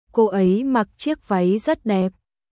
コー エイ マック チエック ヴァイ ザット デップ🔊